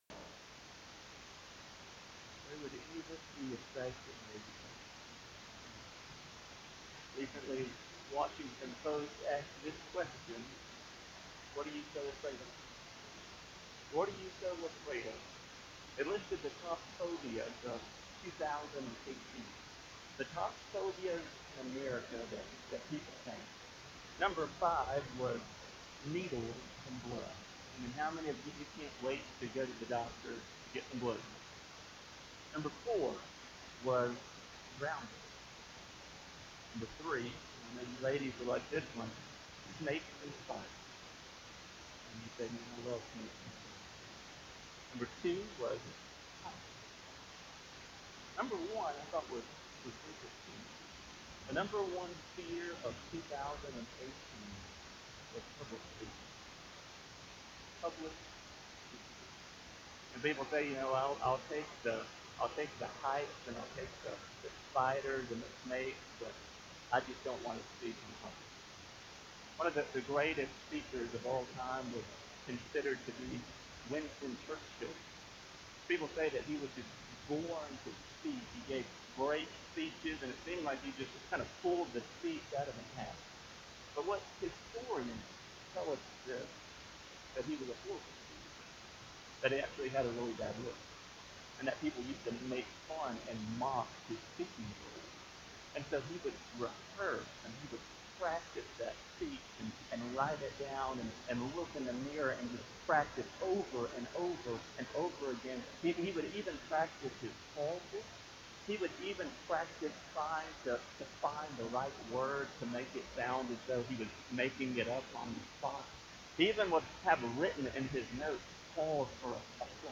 Audio Sermons: Videos of service can be seen on Facebook page - Trinity Baptist Church